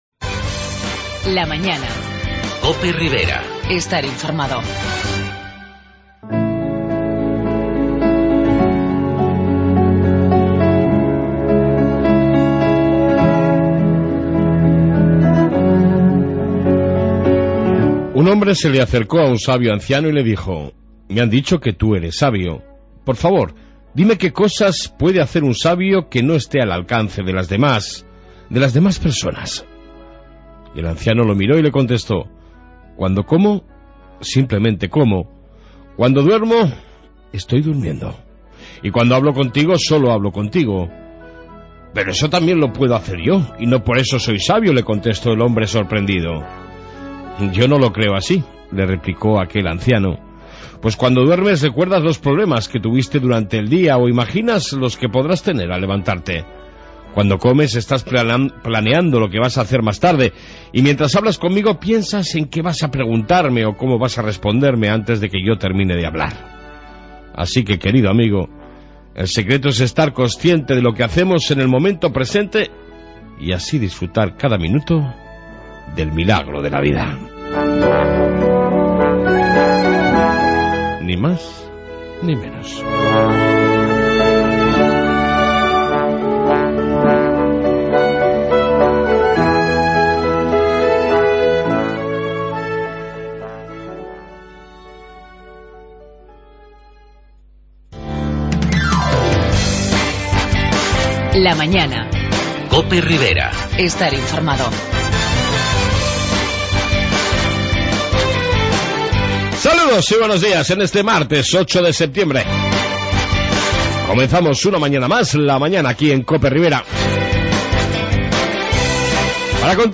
AUDIO: En esta 1ª parte Reflexión Matutina,Info Policía Municipal y amplia entrevista con el portavoz de UPN en Tudela Carlos Moreno